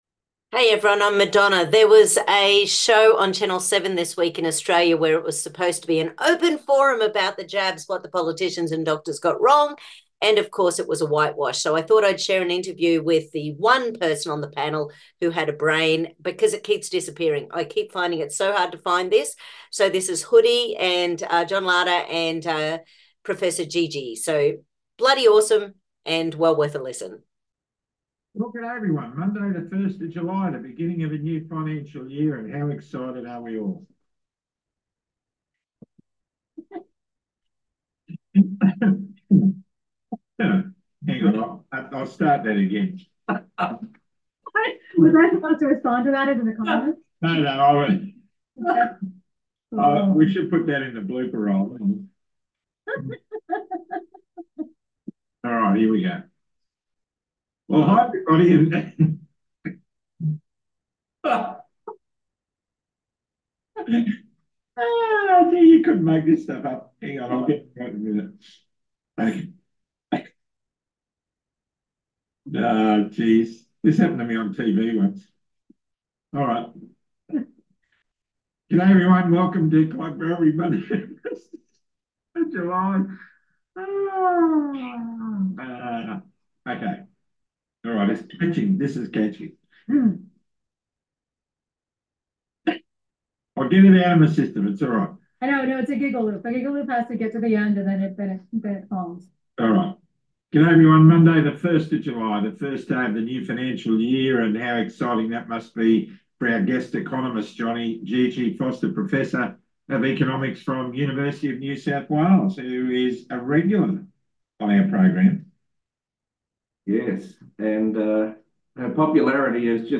Sharing: Interview